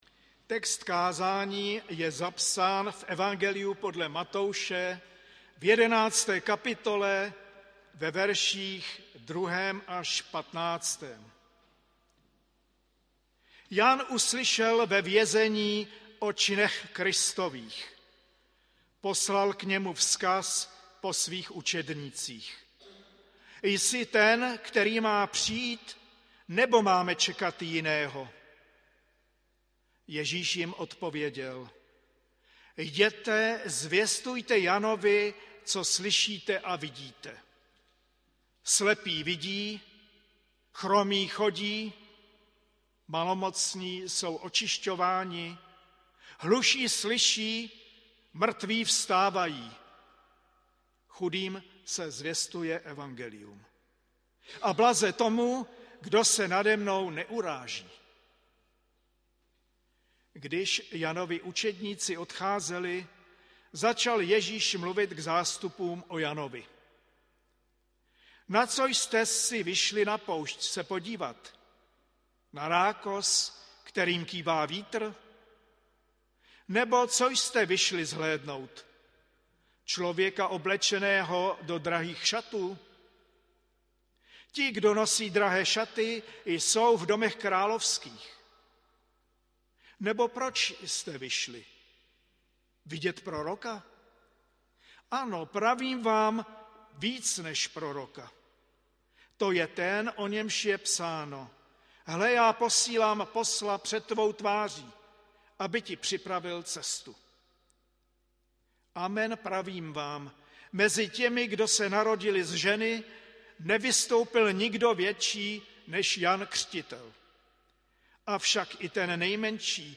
Třetí neděle adventní 11. prosince 2022 AD
audio kázání